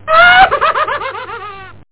PSION CD 2 home *** CD-ROM | disk | FTP | other *** search / PSION CD 2 / PsionCDVol2.iso / Wavs / AHHAHAHA ( .mp3 ) < prev next > Psion Voice | 1998-08-27 | 15KB | 1 channel | 8,000 sample rate | 2 seconds
AHHAHAHA.mp3